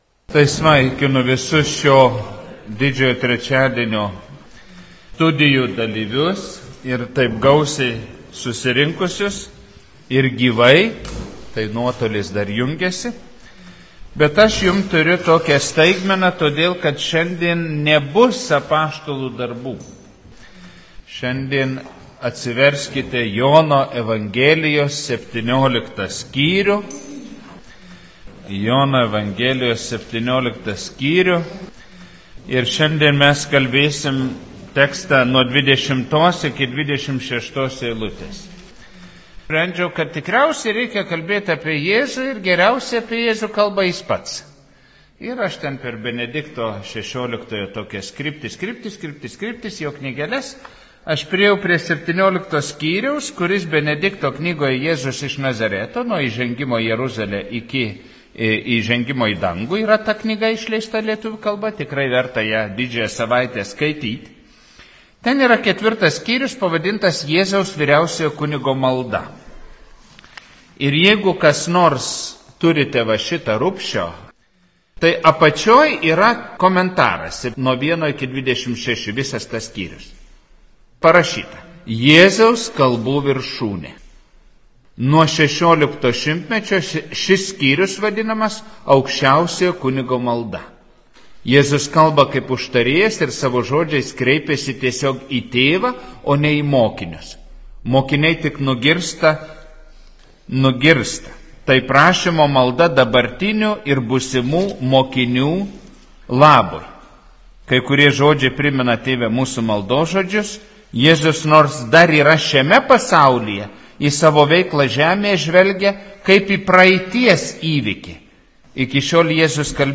Evangelijos tekstas Jn 17, 20-26 Paskaitos audioįrašas